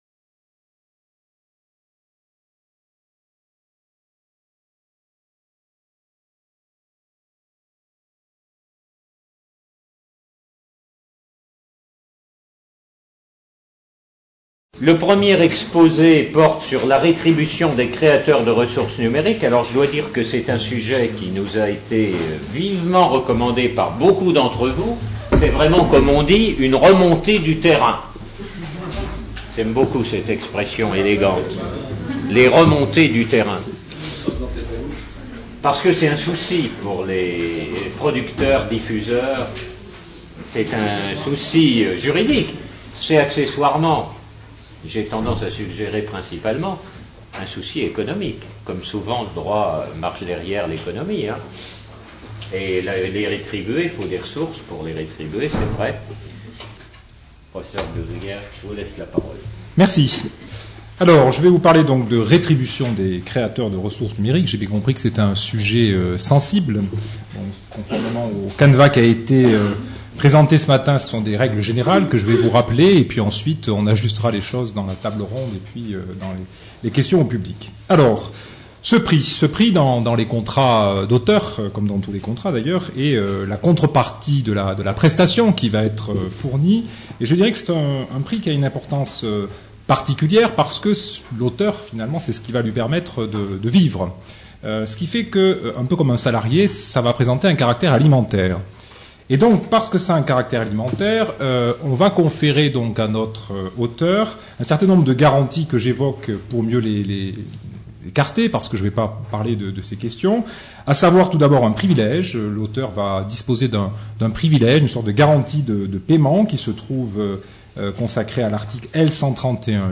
Questions de la salle et réponses des intervenants